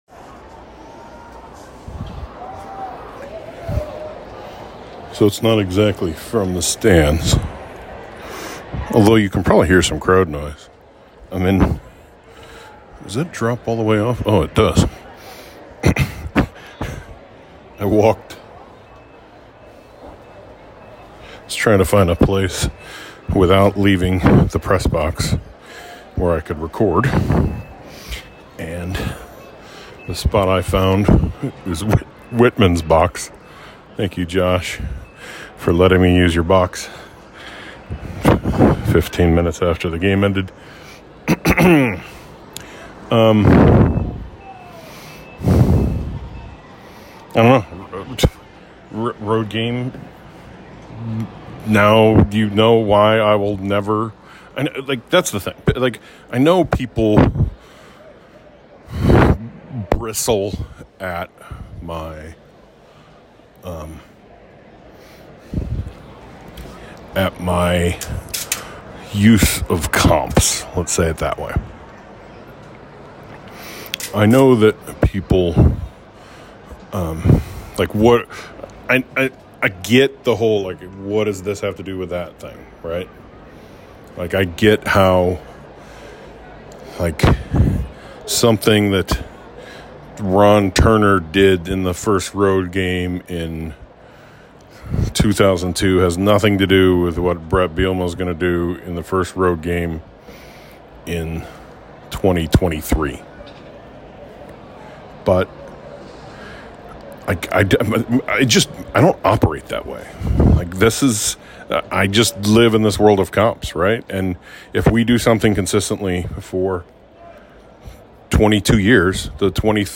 I needed a quiet spot so I went into the empty AD suite after the game and recorded FTS. 1-1 and I feel... not great, Bob.